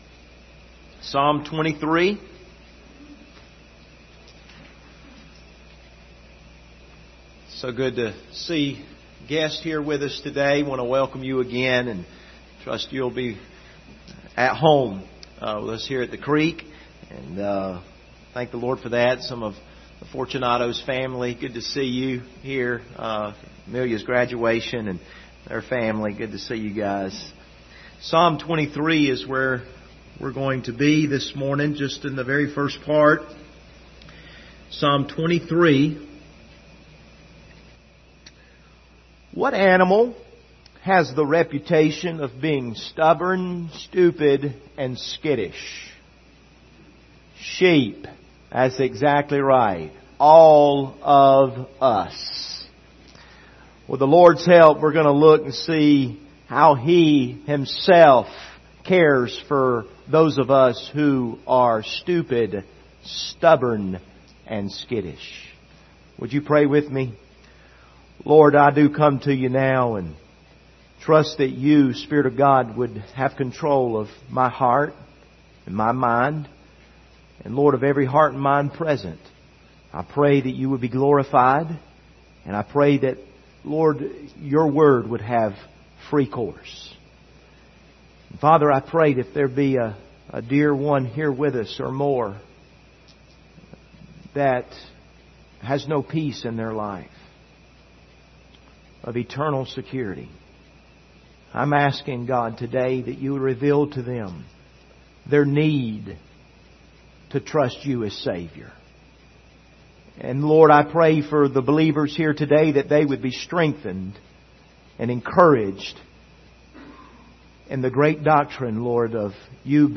Passage: Psalms 23 Service Type: Sunday Morning